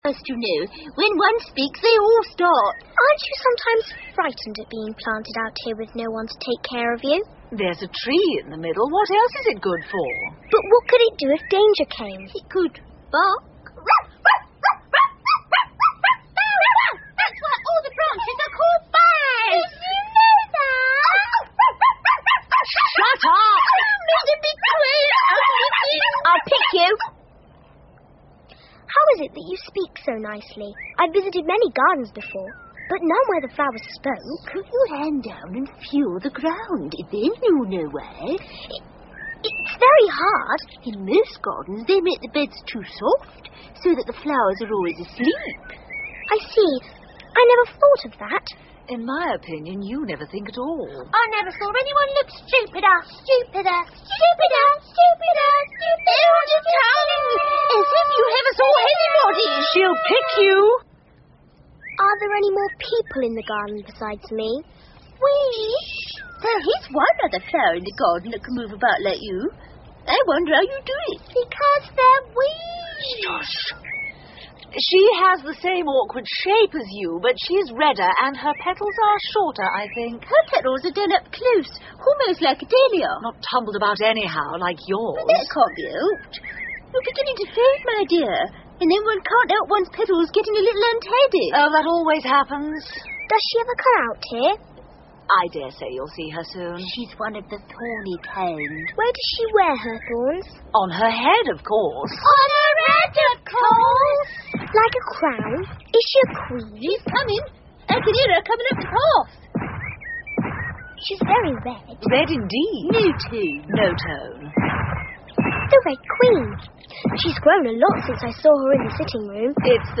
Through The Looking Glas 艾丽丝镜中奇遇记 儿童广播剧 4 听力文件下载—在线英语听力室